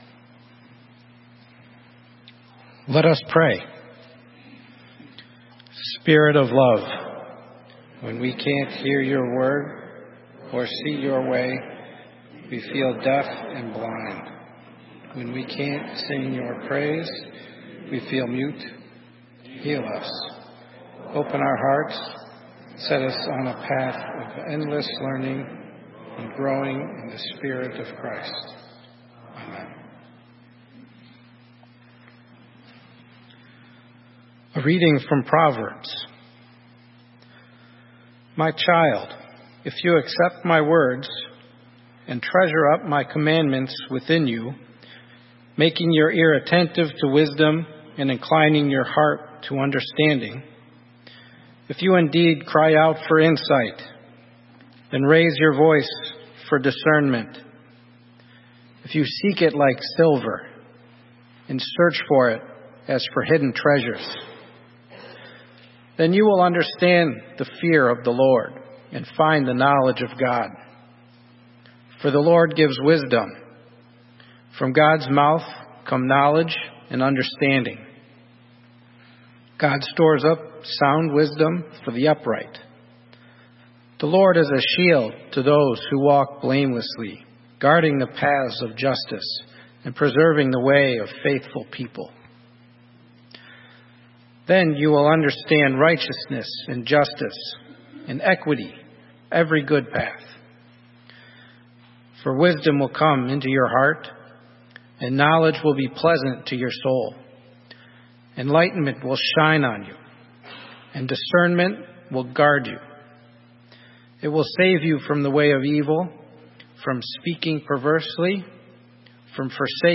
Sermon:Learning a way to live - St. Matthew's UMC